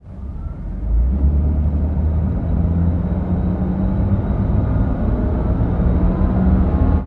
Highway / oldcar / start.ogg